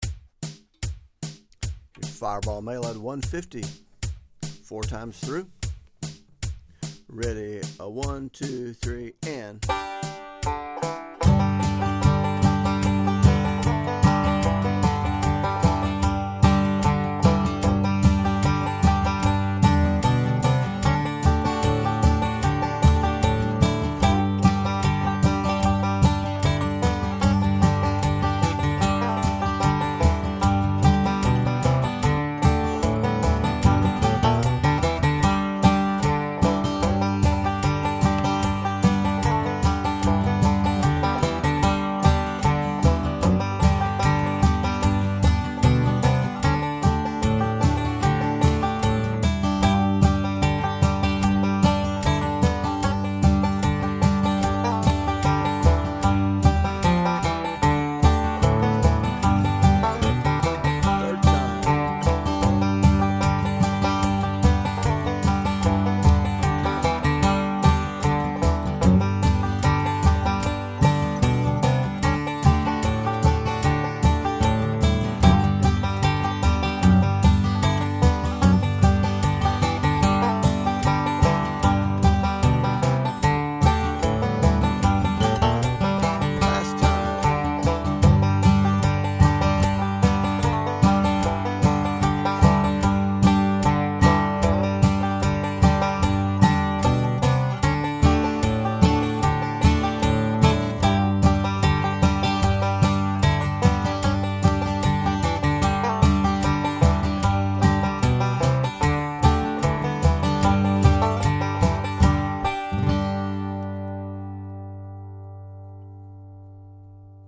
150 bpm